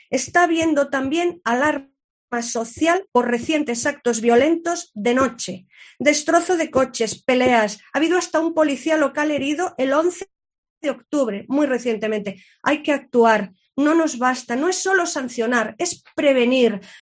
Sonsoles Sánchez-Reyes, portavoz PP. Pleno botellón